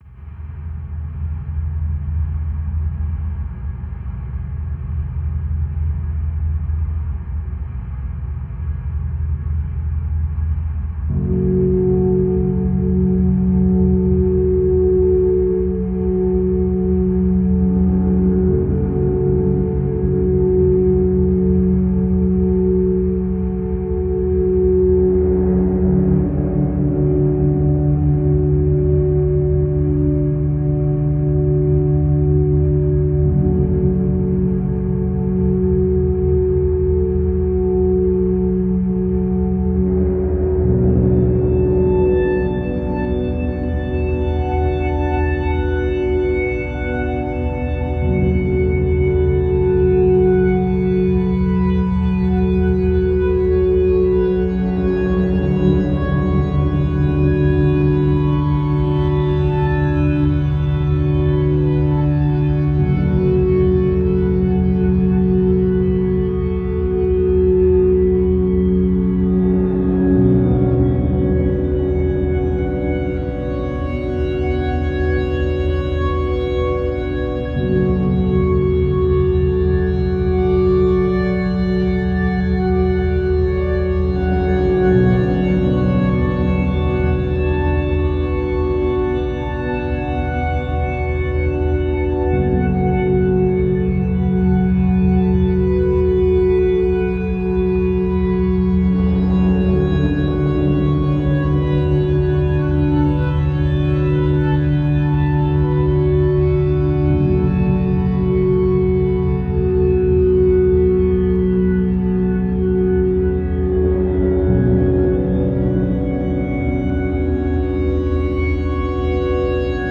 Jeden Tag im Oktober ein Musikstück für die richtige Halloween-Stimmung.